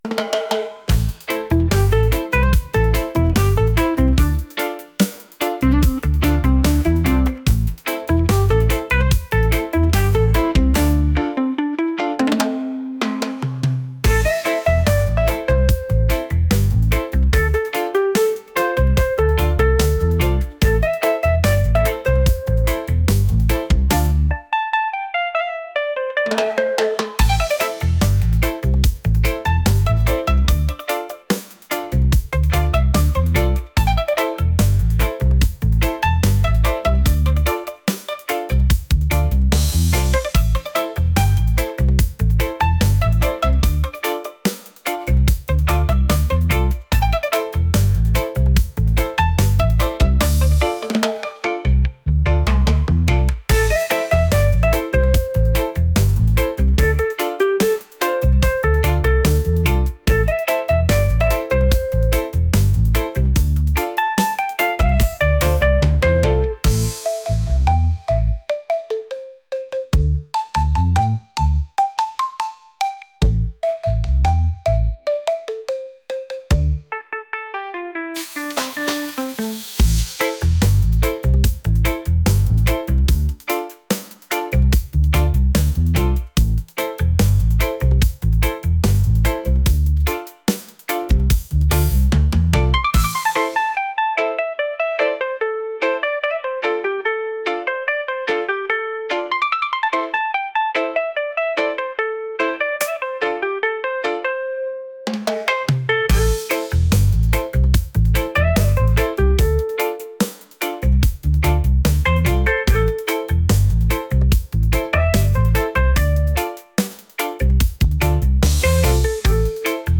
rhythmic | reggae